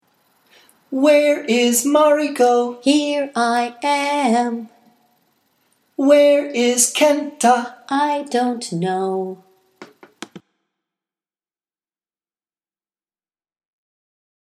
It requires the simplest of melodies:  Sol – Mi – Sol – Mi  and Sol – Mi – Do
roll-call-song.mp3